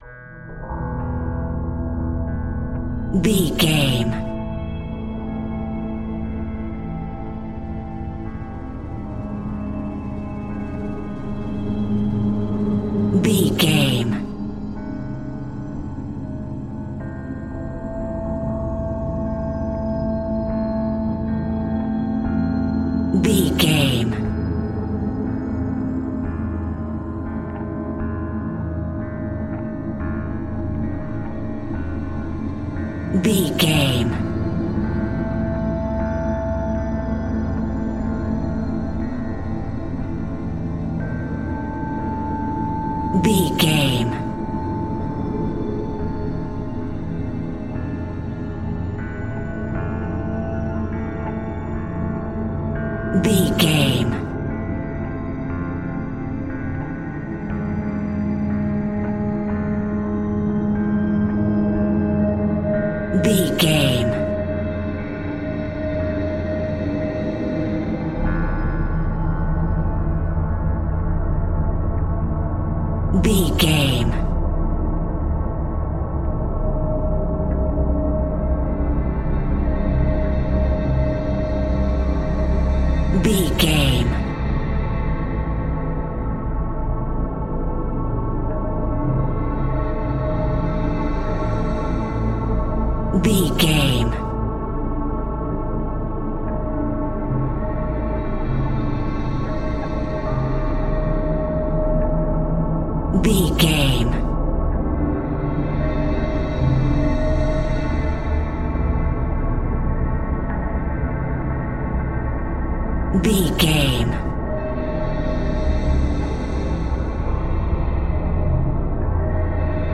Thriller
Ionian/Major
A♭
industrial
dark ambient
EBM
drone
synths
Krautrock